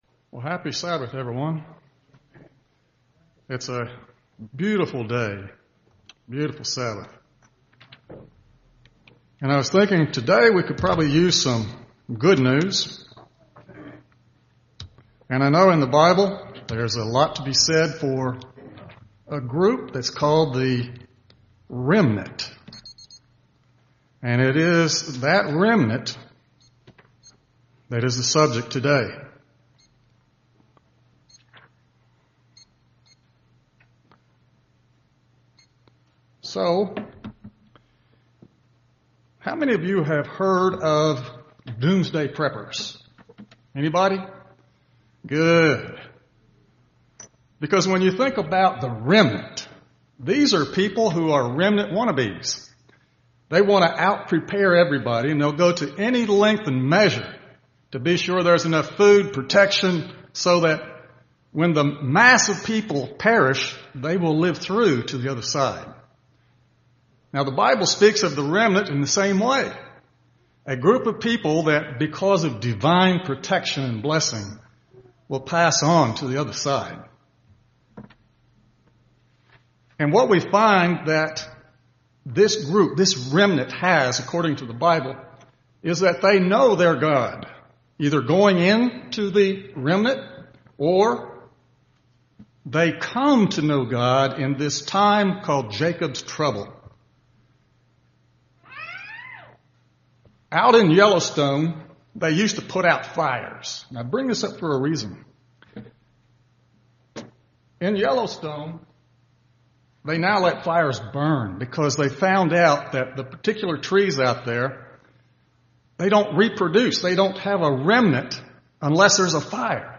Given in Birmingham, AL
UCG Sermon Studying the bible?